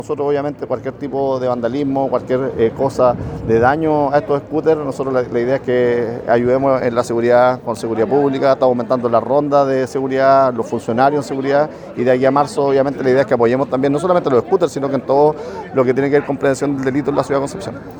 El alcalde de Concepción, Héctor Muñoz, dijo que esperan colaborar en la seguridad para mantener estos ciclos funcionando en la ciudad.
cuna-scooter-alcalde.mp3